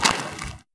pedology_turf_footstep.3.ogg